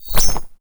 potion_flask_mana_collect_04.wav